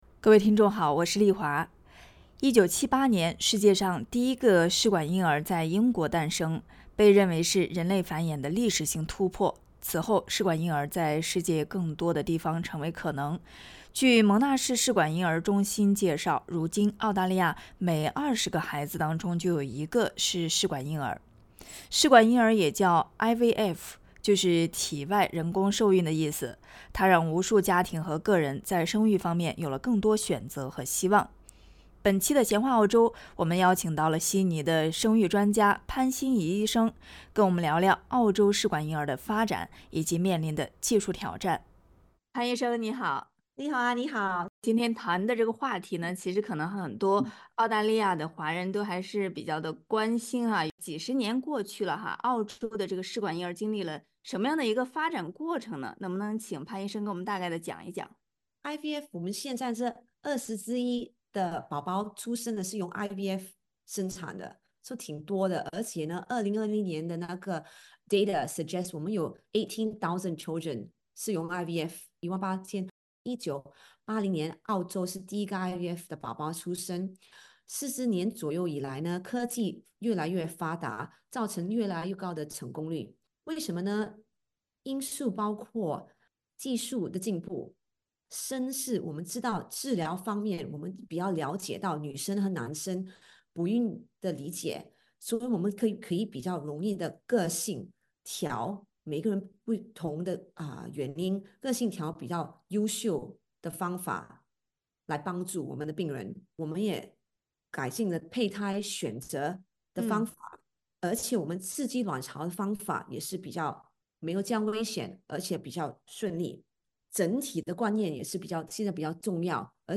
科技的不断进步特别是人工智能快速发展可能会给试管婴儿带来什么影响？点击上方音频收听采访。